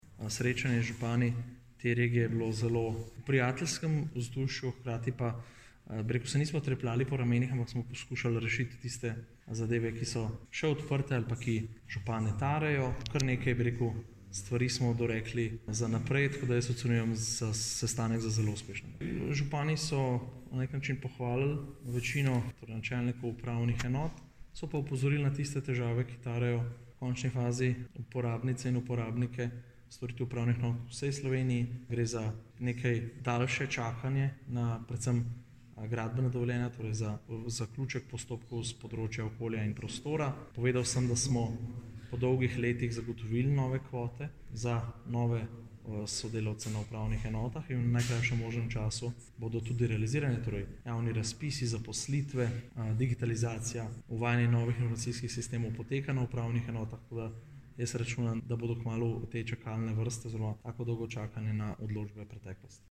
izjava_bostjankoritnikministerzajavnoupravo.mp3 (1,5MB)